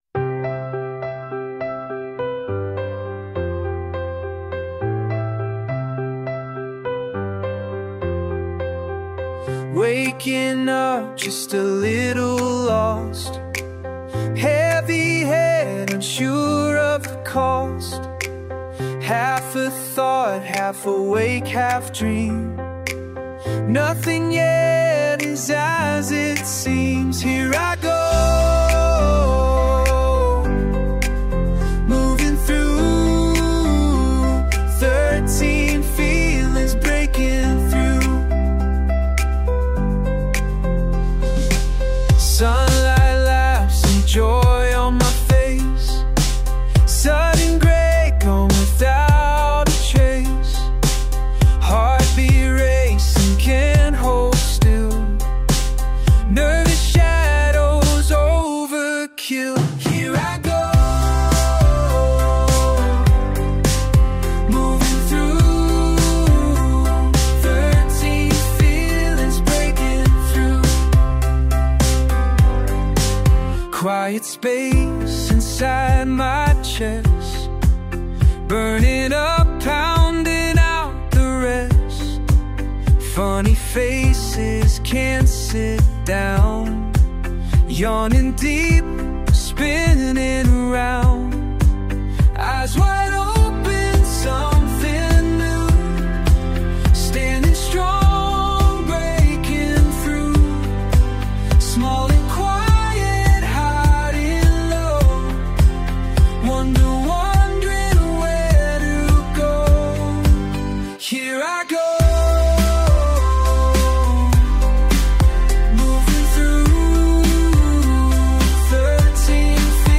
Two emotion-shifting songs (provided or selected for changes in energy/mood) Riffusion created Shifting Moods Song 1 – Can only be used for non-commercial use. Riffusion created Shifting Moods Song 2 – Can only be used for non-commercial use.